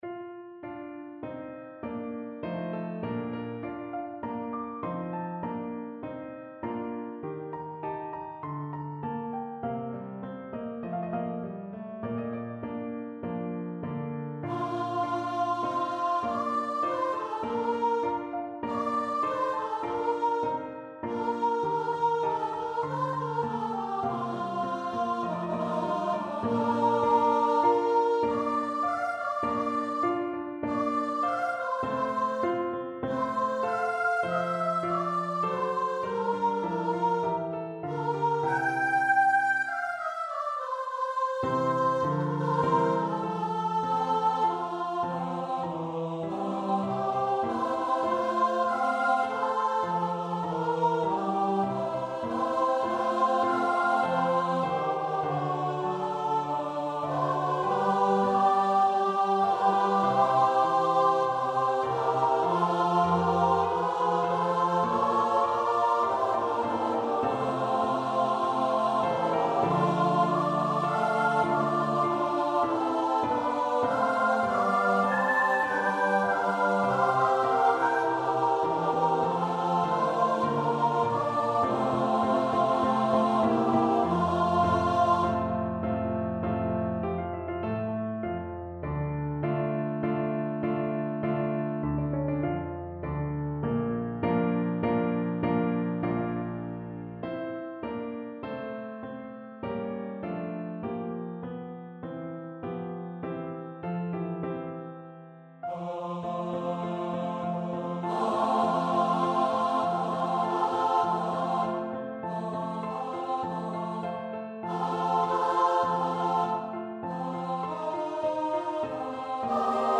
Benedictus (Requiem) Choir version
Choir  (View more Intermediate Choir Music)
Classical (View more Classical Choir Music)